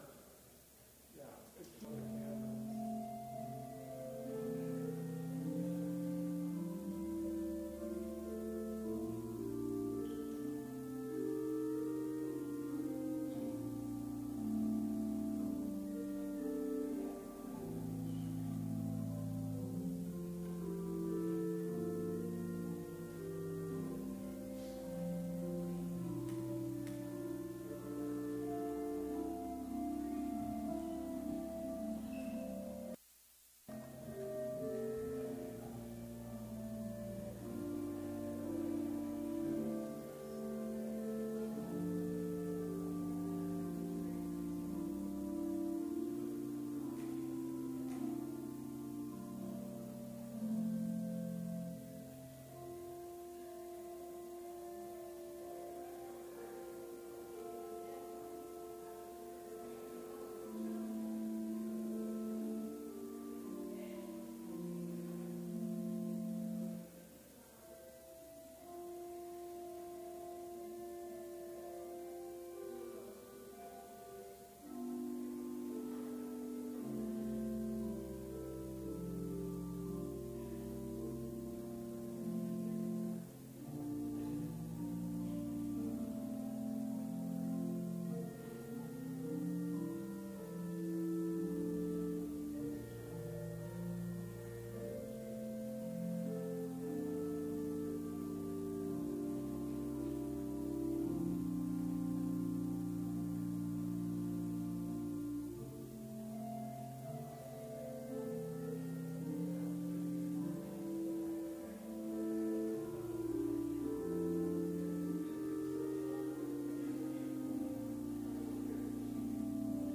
Complete service audio for Chapel - December 19, 2018